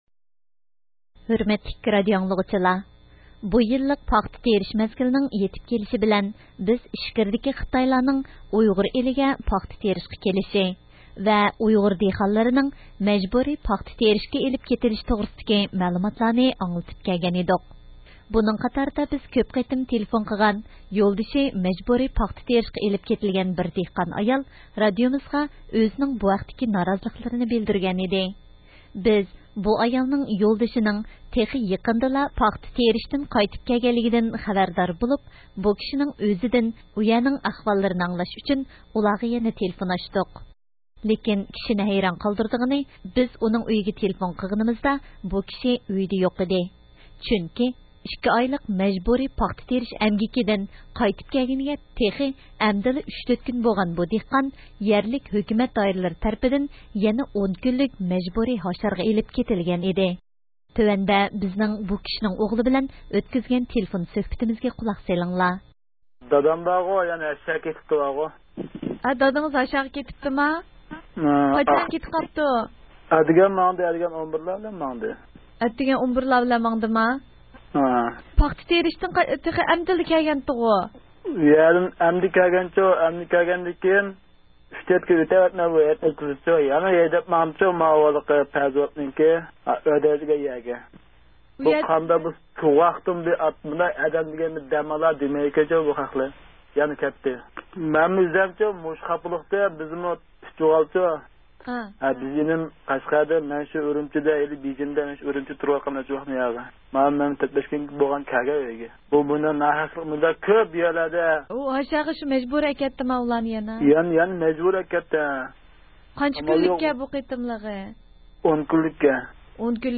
مەزكۇر دېھقاننىڭ ئايالى ۋە ئوغلى بىلەن تېلېفوندا سۆھبەتلىشىش ئاساسىدا تەييارلىغان مەخسۇس پروگراممىسىدىن ئاڭلاڭ.